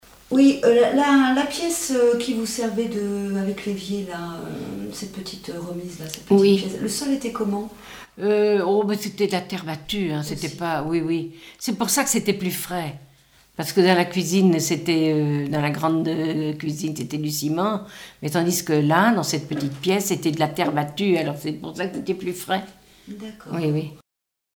Témoignages sur la vie à la ferme
Catégorie Témoignage